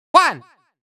countIn1Far.wav